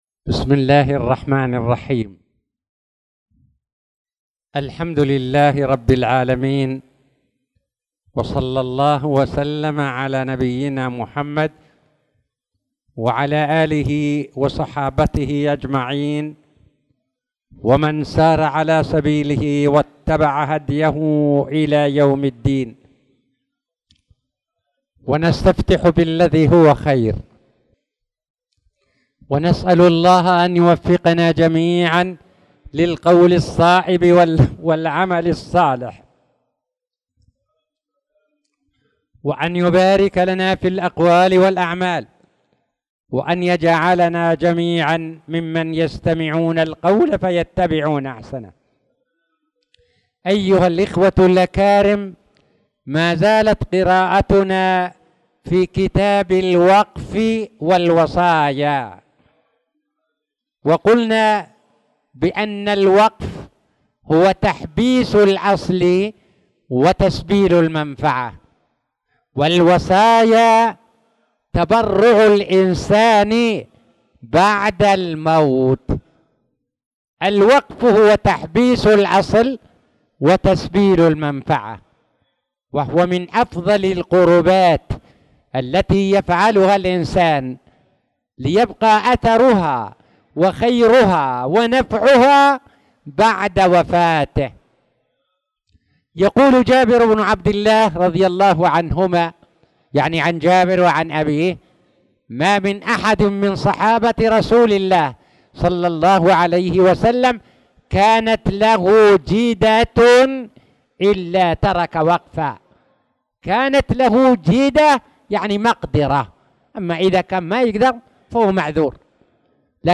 تاريخ النشر ١١ شعبان ١٤٣٧ هـ المكان: المسجد الحرام الشيخ